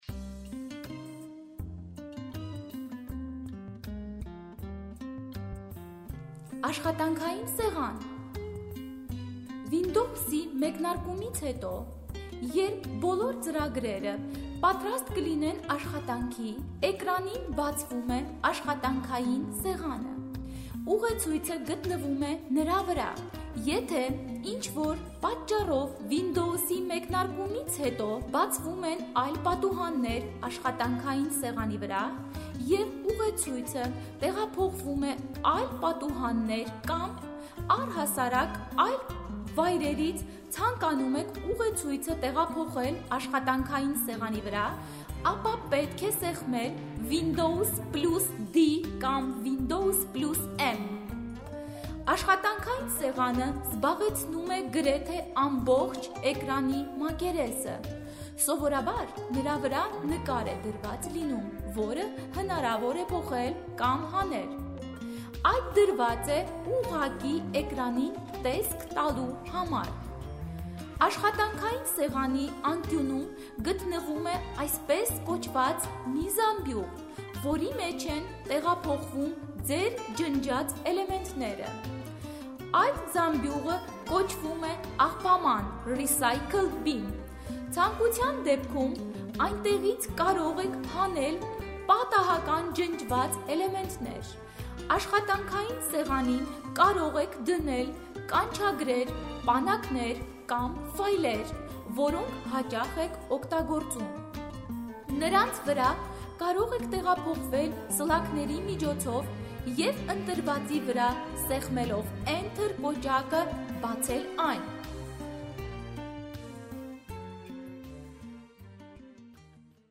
Звуковой учебник для начинающих незрячих пользователей компьютера на армянском языке.